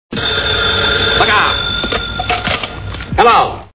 Sfx: Phone rings.